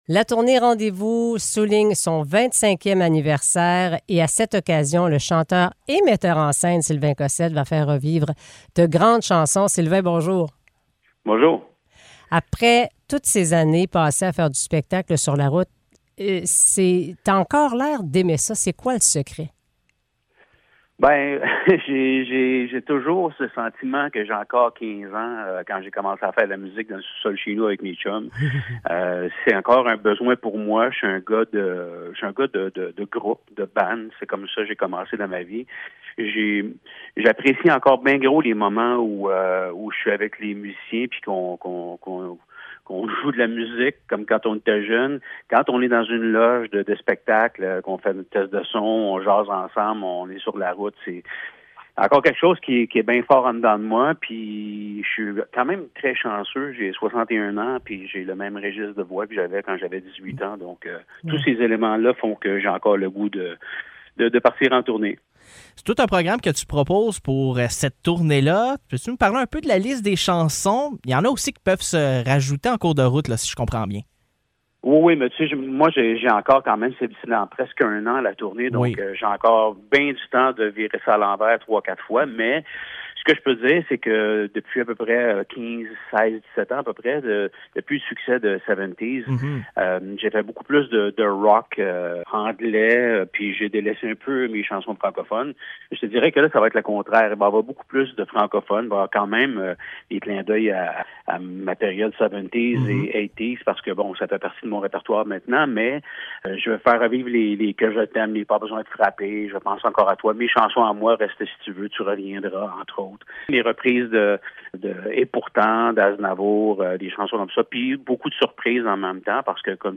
Entrevue avec Sylvain Cossette